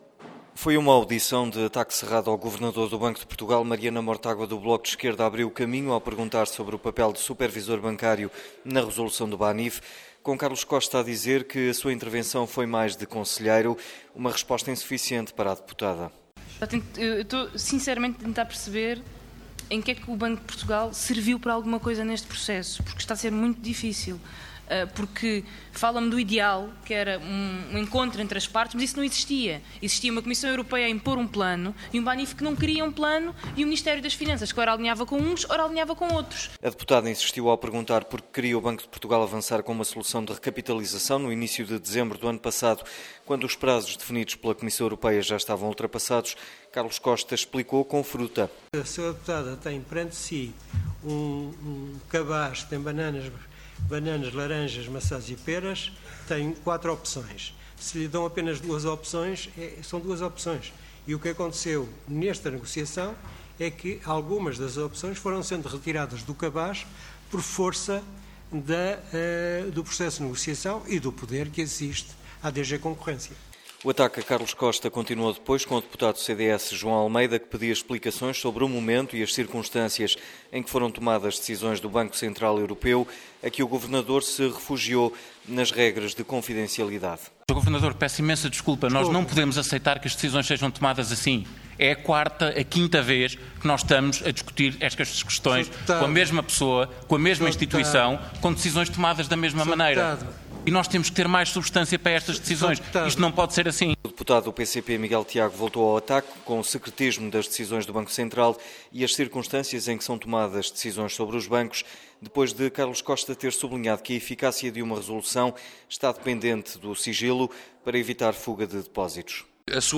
O governador do Banco de Portugal, Carlos Costa, esteve esta terça-feira sob ataque cerrado na comissão de inquérito parlamentar à resolução do Banco Internacional do Funchal (Banif).